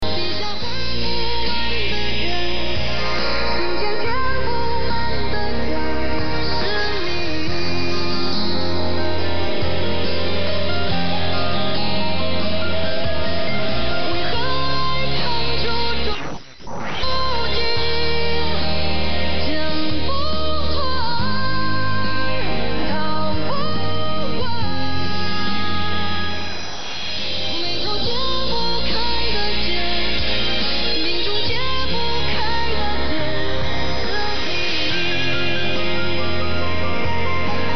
Попробовал на скорую руку в программе HDSDR записать вещательные станции, используя IQ выход TRX.вот что получилось:
AM_IQ.mp3